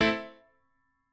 admin-leaf-alice-in-misanthrope/piano34_9_009.ogg at main